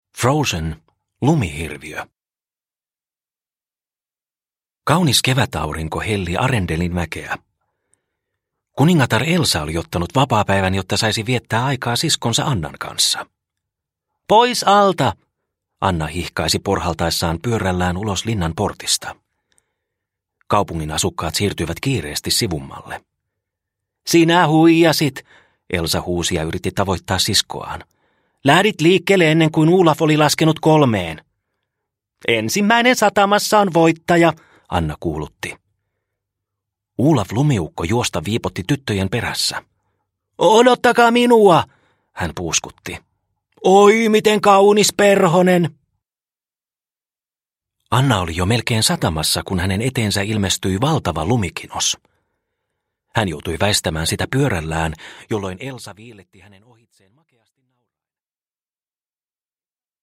Frozen. Lumihirviö – Ljudbok – Laddas ner